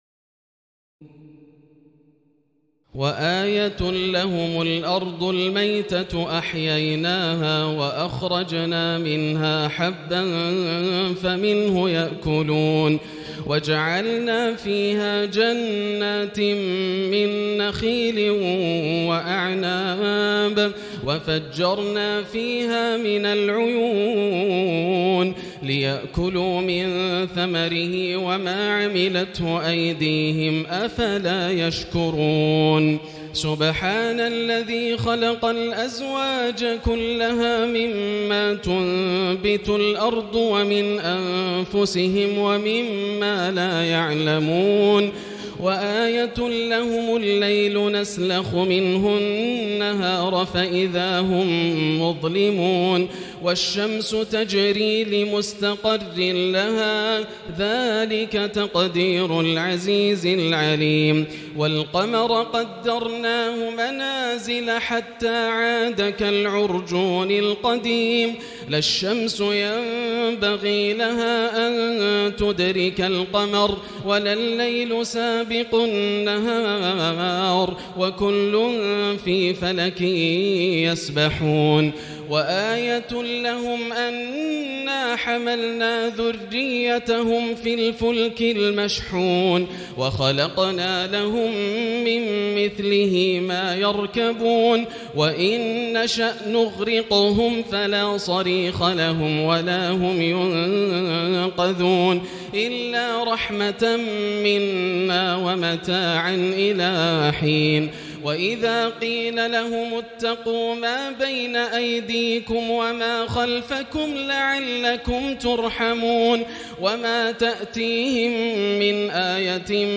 تراويح ليلة 22 رمضان 1439هـ من سور يس (33-83) والصافات(1-138) Taraweeh 22 st night Ramadan 1439H from Surah Yaseen and As-Saaffaat > تراويح الحرم المكي عام 1439 🕋 > التراويح - تلاوات الحرمين